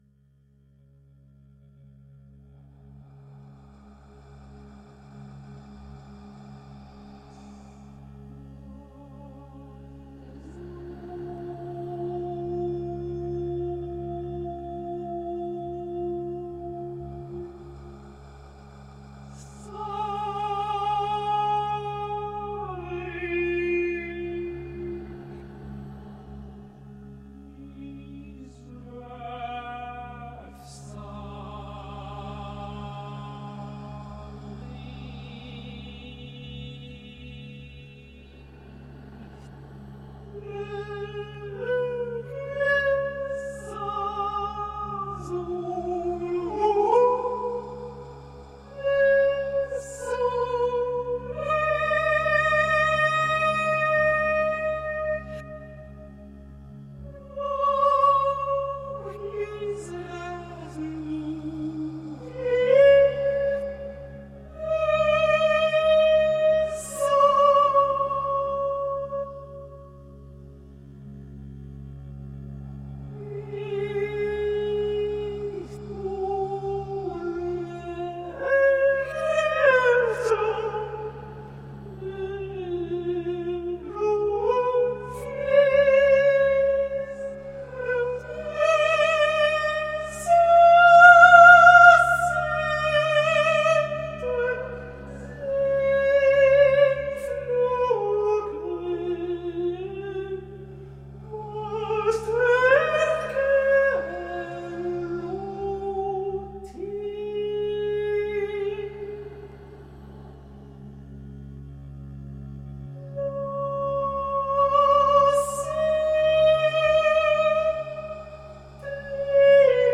New Music